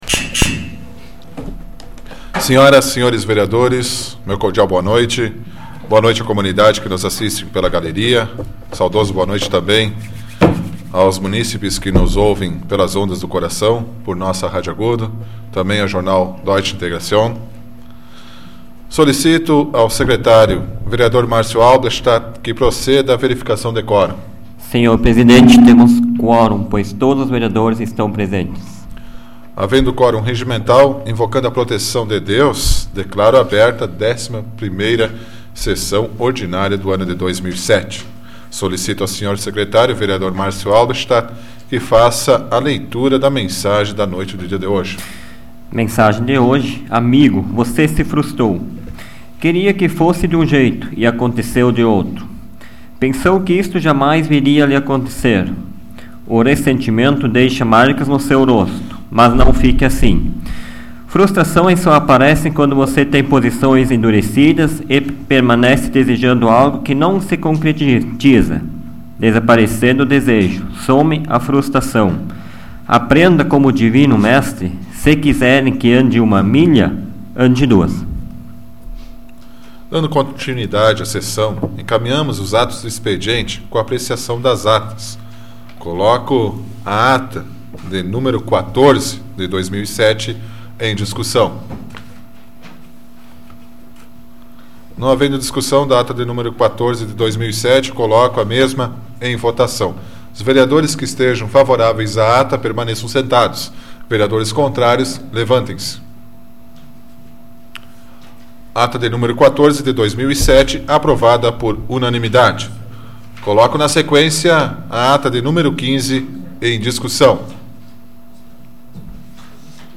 Áudio da 85ª Sessão Plenária Ordinária da 12ª Legislatura, de 14 de maio de 2007